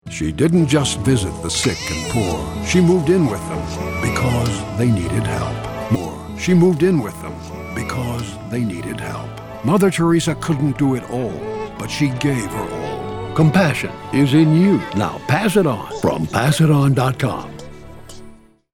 Mother Teresa showed incredible compassion by caring for thousands of sick and poor in orphanages and hospices. Listen to the Radio Commercial and Pass it On.